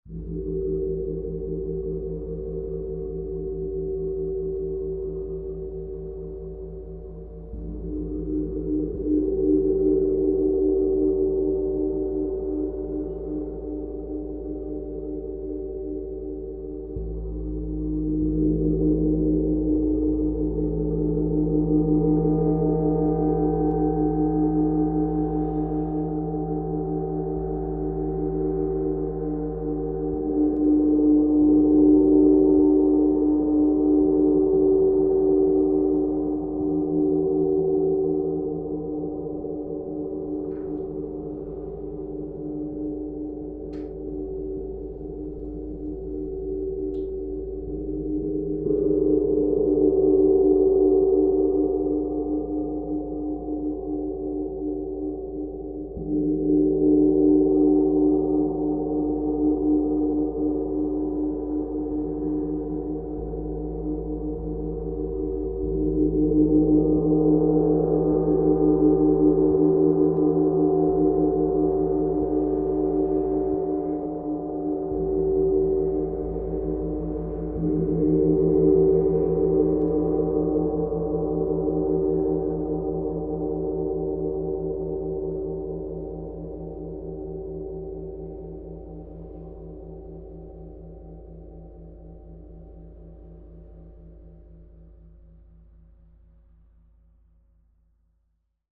Esta grabación es la real del Gong disponible